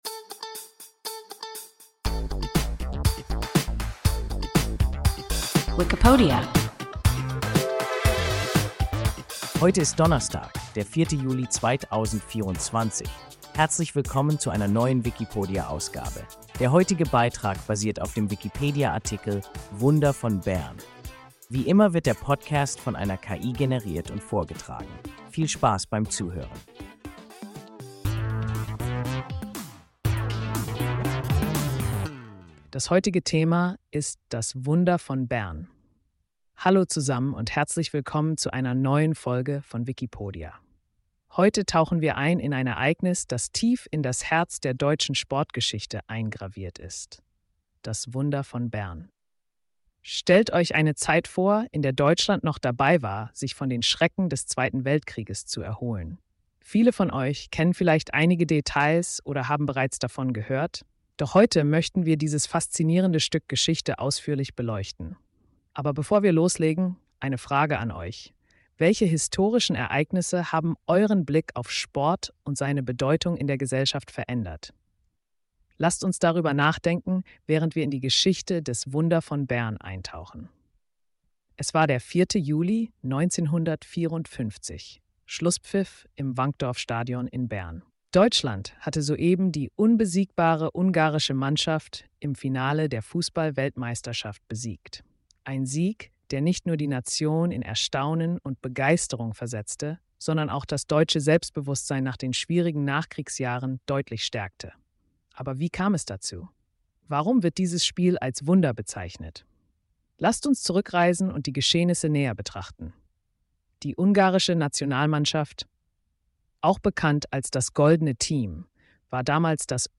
Wunder von Bern – WIKIPODIA – ein KI Podcast